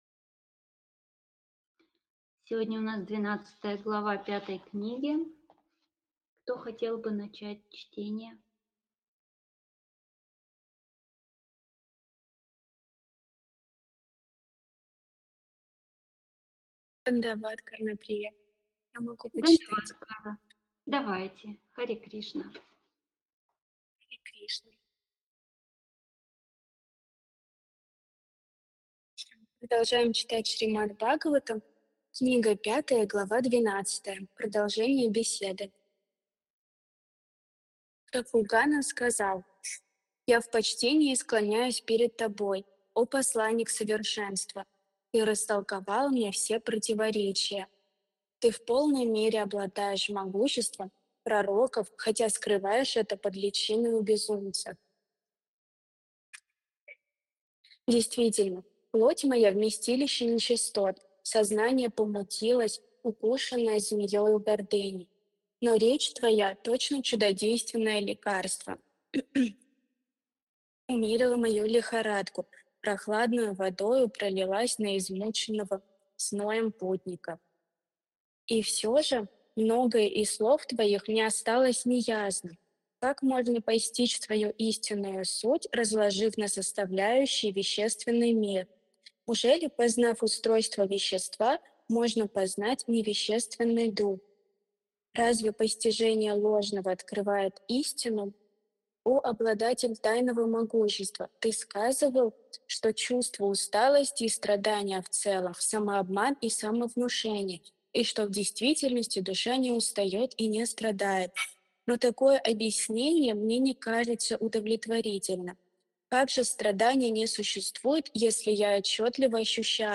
Ответы на вопросы из трансляции в телеграм канале «Колесница Джаганнатха».